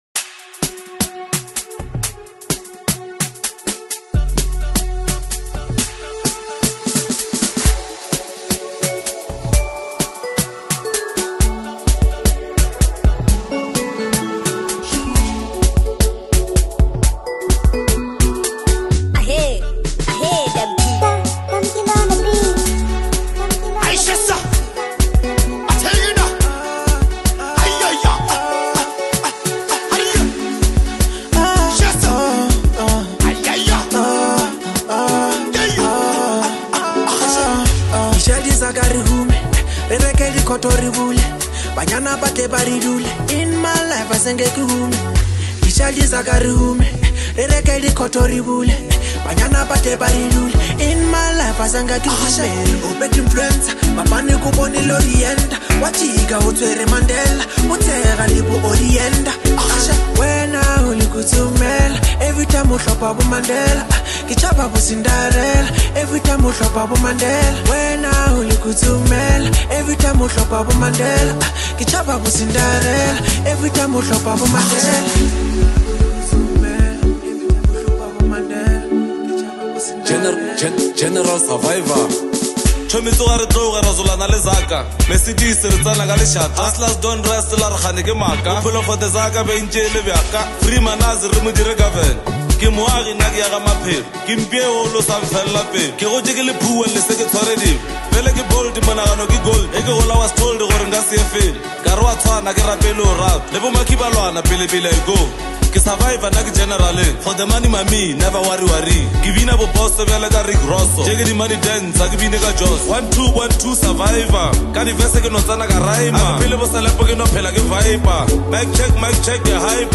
lekompo